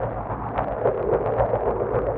Index of /musicradar/rhythmic-inspiration-samples/110bpm